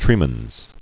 (trēmənz)